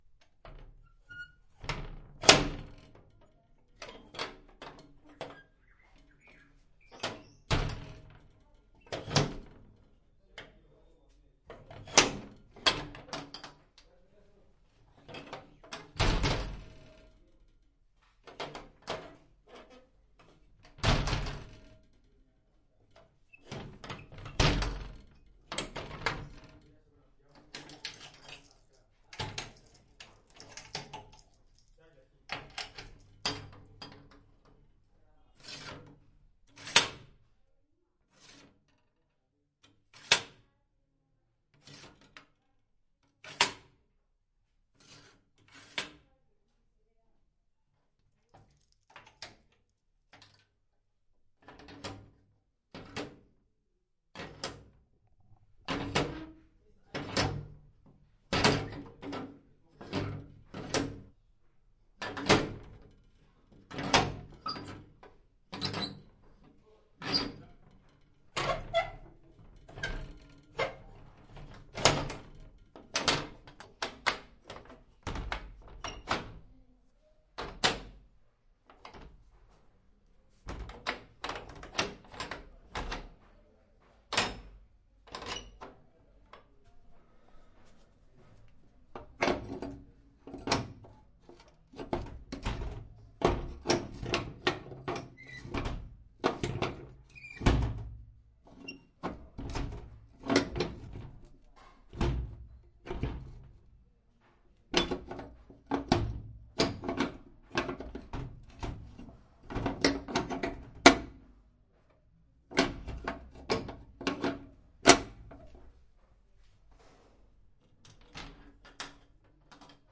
乌干达 " 金属厚重的地牢监狱门开闩打开关闭砰砰作响，舱门打开关闭滑动吱吱作响，各种角度都有。
描述：金属重型地牢重金属监狱门解锁打开关闭thud拨浪鼓和舱口打开关闭幻灯片吱吱声各种int透视onmic.wav